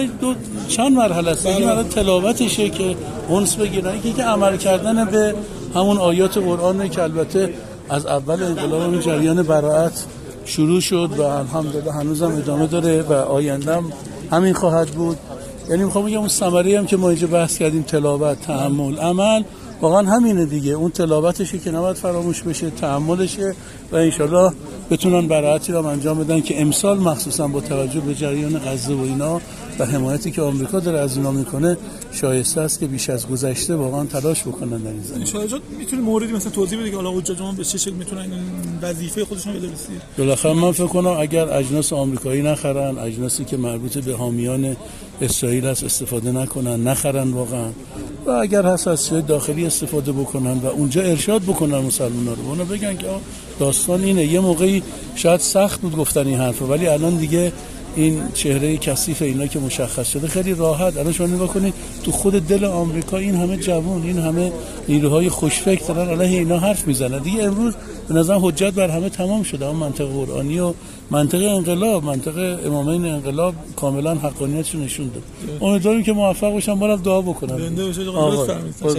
عضو مجلس خبرگان رهبری در گفت‌وگو با ایکنا، درخصوص منطق قرآنی برائت و نقش حجاج در تبلیغ این موضوع با توجه به تأکید مقام معظم رهبری بر تبلیغ منطق قرآنی برائت از سوی حجاج گفت: انتقال صحیح منطق قرآنی «حج برائت» از سوی حجاج چند بخش دارد که قدم اول آن انس با قرآن کریم و قدم بعدی تأمل در آیات و در نهایت عمل کردن به قرآن کریم است.